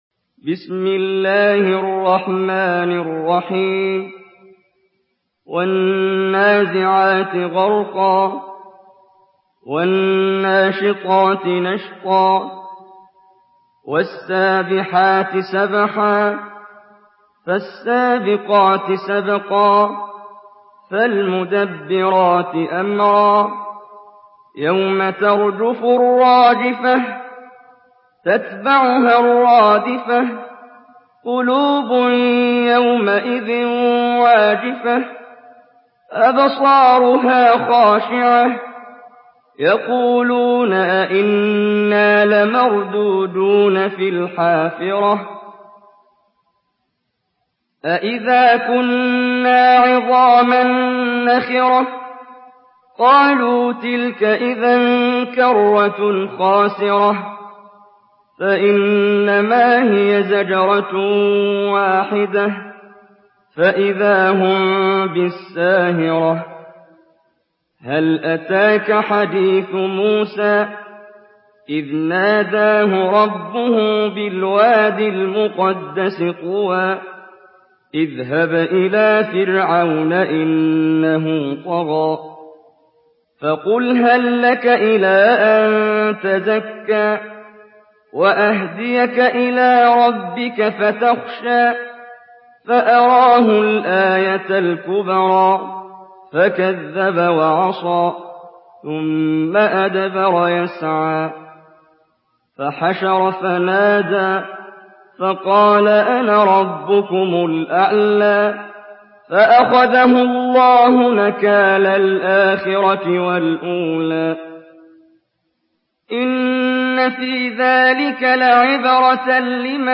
Surah An-Naziat MP3 in the Voice of Muhammad Jibreel in Hafs Narration
Murattal Hafs An Asim